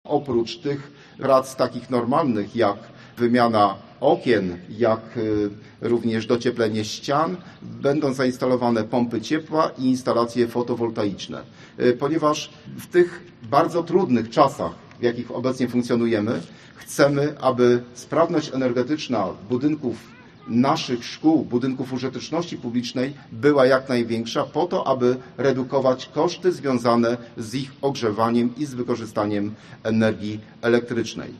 O inwestycji mówił podczas konferencji prasowej w Zaskrodziu, Wójt Gminy Kolno, Józef Bogdan Wiśniewski: